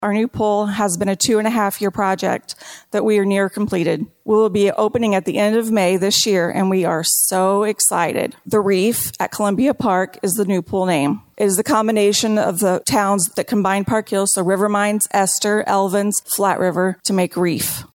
(Park Hills, MO) Park Hills Officials are working towards the future after last week's State of the County Address which featured presentations from area mayors including Stacey Easter of Park Hills. Easter told the crowd they would be opening the city's new pool soon.